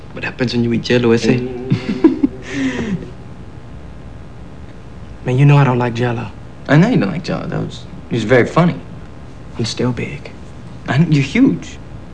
Sounds From the Generation X telefilm